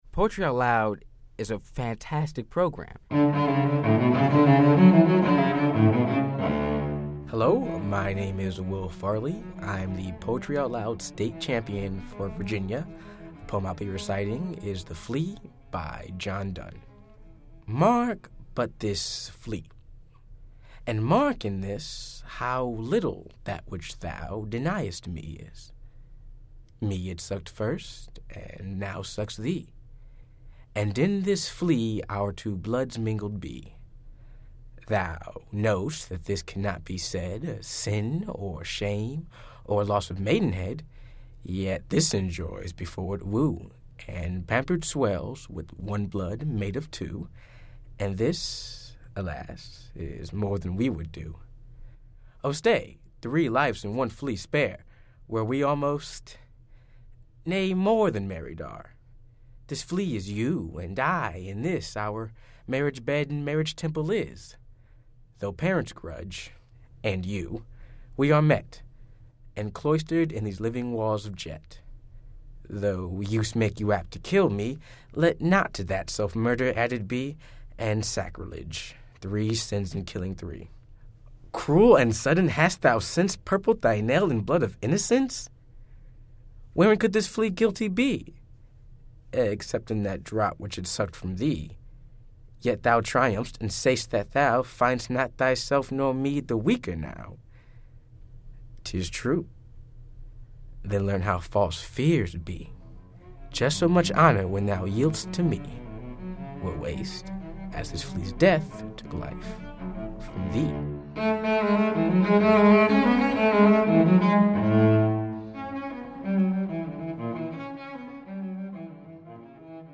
Music up and fades out